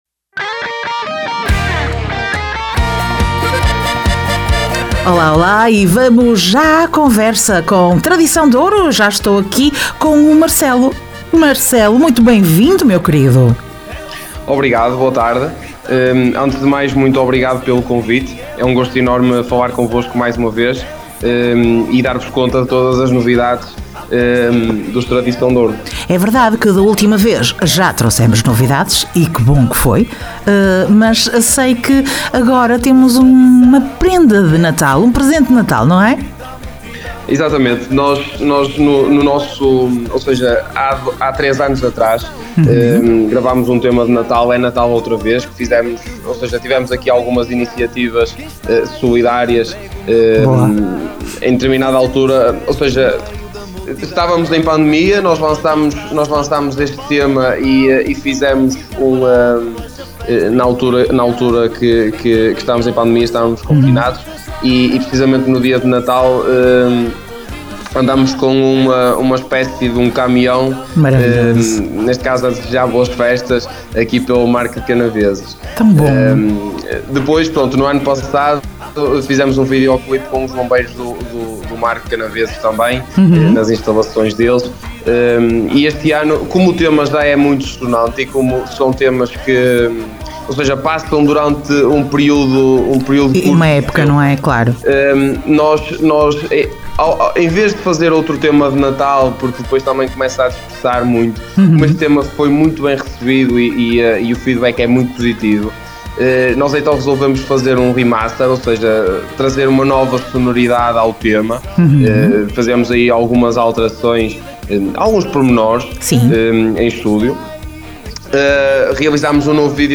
Entrevista Tradição D`Ouro